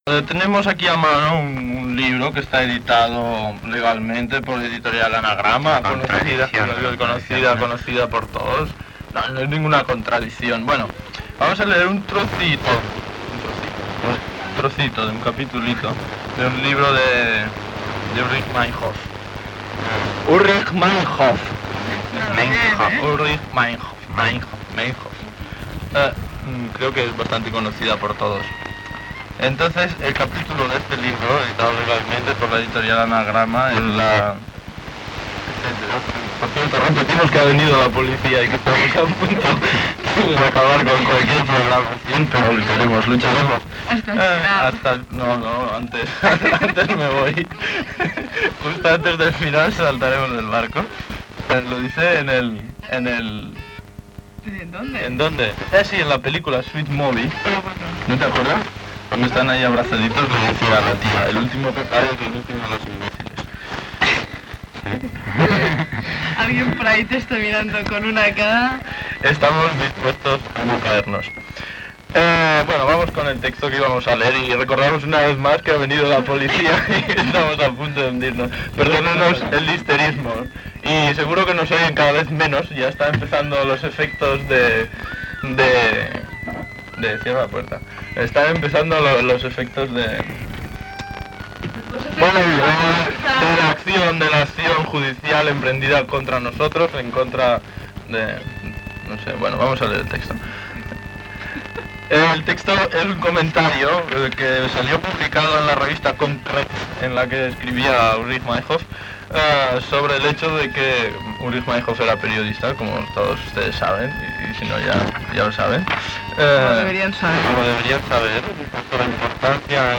5169ecfe36a65ab459126bc4cf2279a1fc4697e4.mp3 e23a922bbf4b32a45d117b4c9faac4342d364b21.mp3 Títol Ona Lliure Emissora Ona Lliure Titularitat Tercer sector Tercer sector Lliure Anunciant Emissió en els moments del quart tancament de l'emissora.